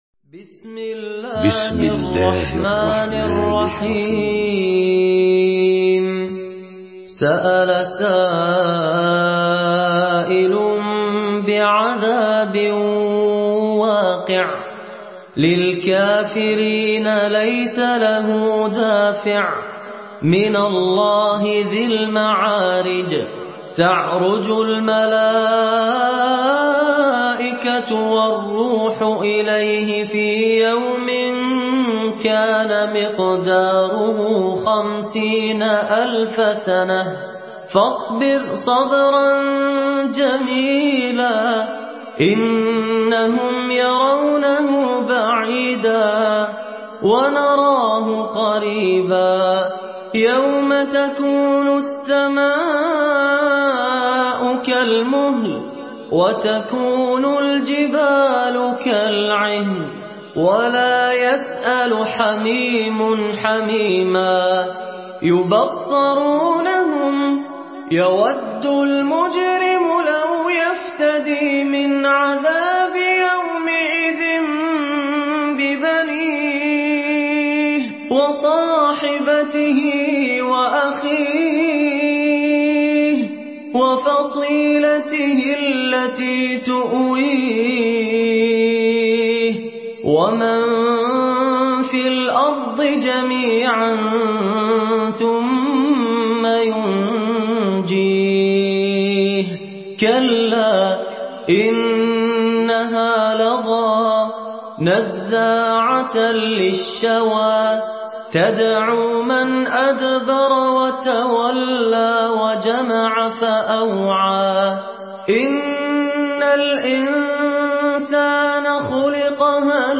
سورة المعارج MP3 بصوت فهد الكندري برواية حفص
مرتل